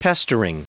Prononciation du mot pestering en anglais (fichier audio)
Prononciation du mot : pestering